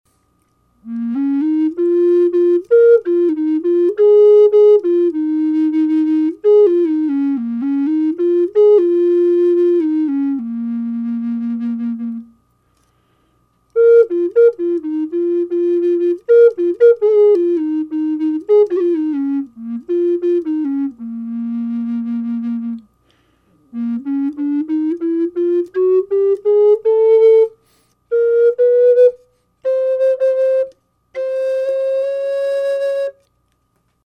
Sound sample of  this Flute short melody + 16 notes scale  without reverb/raw unprocessed
low-A-sharp-Bass.mp3